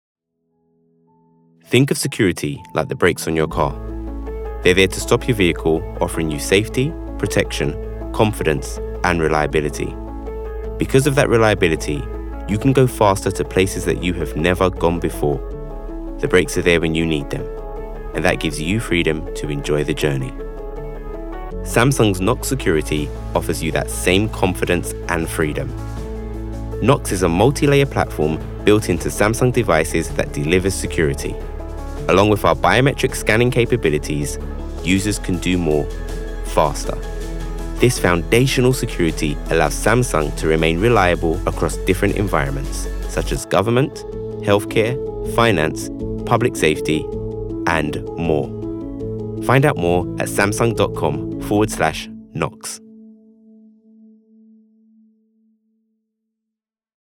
Cool, clear and confident.
• Male